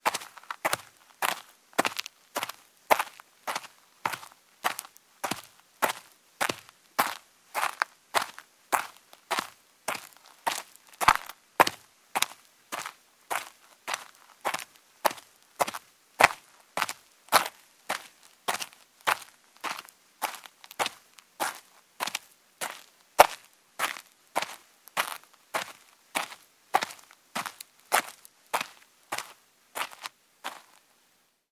Звуки шагов по земле
На этой странице собрана коллекция реалистичных звуков шагов по различным типам земной поверхности.
Шаги по грунтовой дороге в резиновой обуви